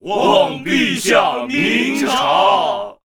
文件 文件历史 文件用途 全域文件用途 Erze_amb_01.ogg （Ogg Vorbis声音文件，长度0.0秒，0 bps，文件大小：36 KB） 源地址:游戏语音 文件历史 点击某个日期/时间查看对应时刻的文件。